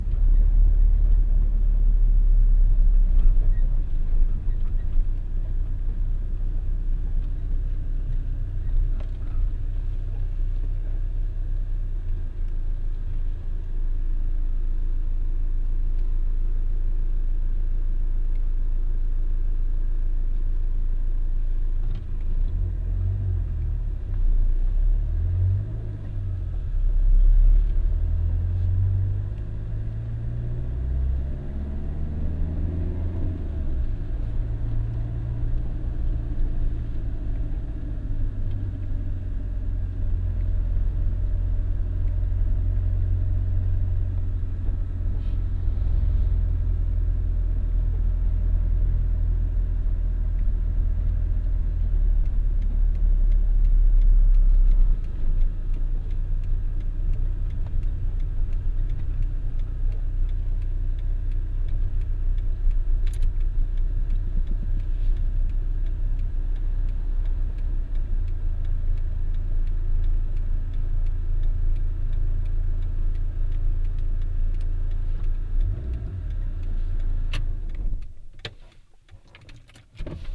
Car driving
Category: Sound FX   Right: Personal